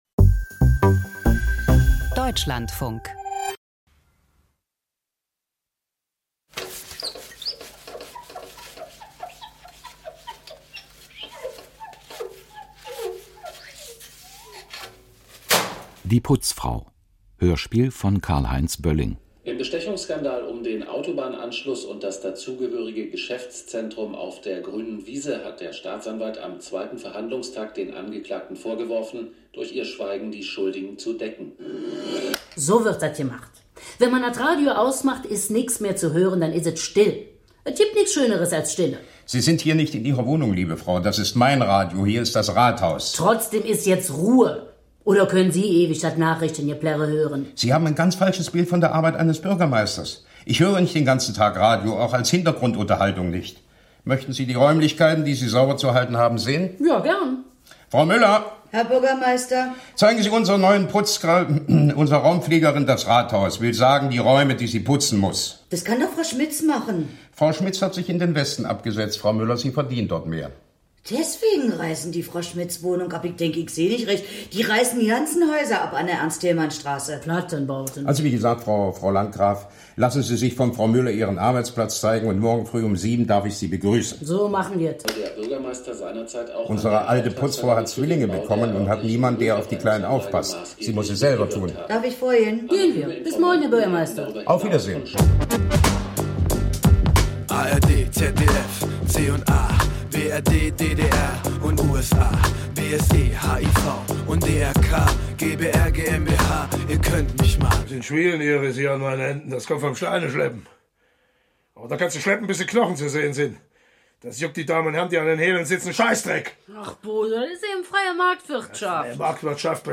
Krimi-Hörspiel: Geiselnahme im Rathaus - Die Putzfrau